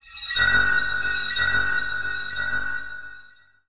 metinstone_detector.wav